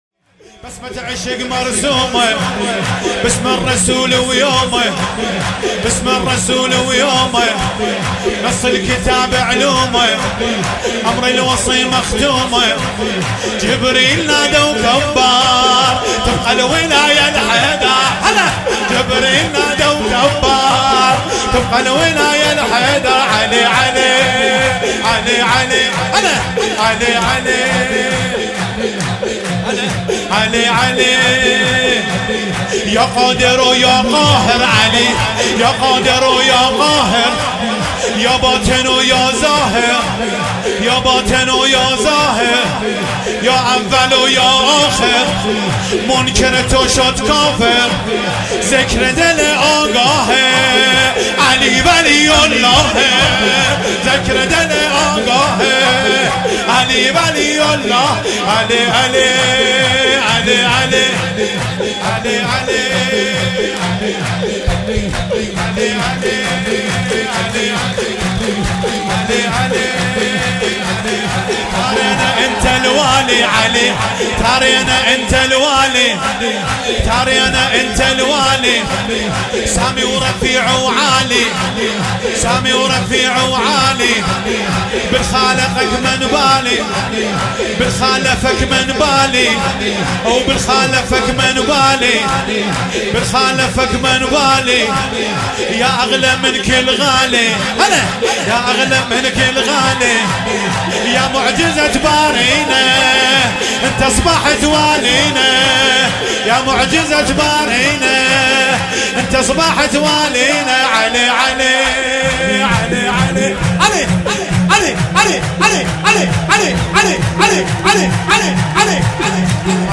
سرود- بسمة عشق مرسومه (عربی ، فارسی)
ولادت حضرت علی(ع)